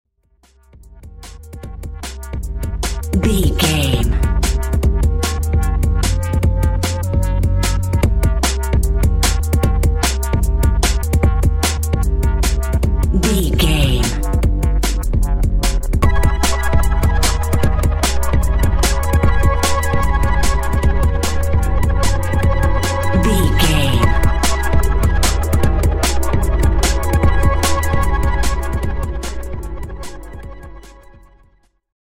Grooving on Drum and Bass.
Aeolian/Minor
Fast
futuristic
hypnotic
industrial
mechanical
dreamy
frantic
synthesiser
drum machine
break beat
sub bass
synth lead
synth bass